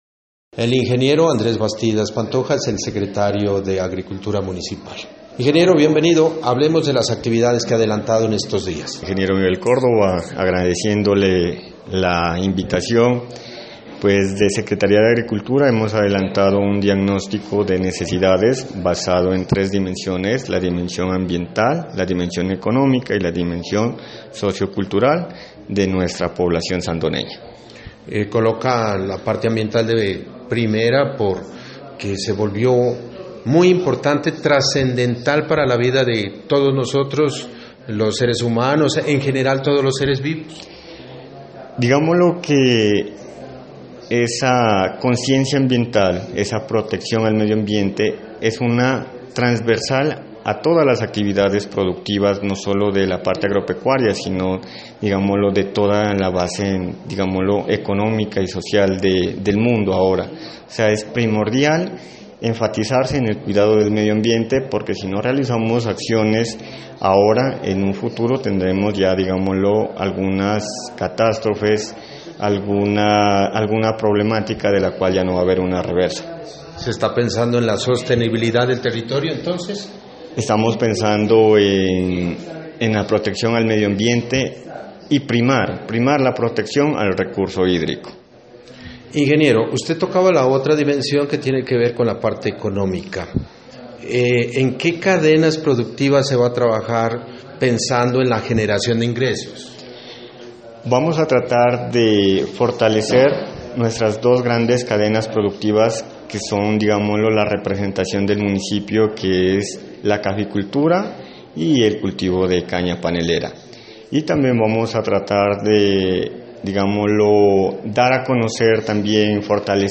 Entrevista con el ingeniero agrónomo Andrés Bastidas Pantoja: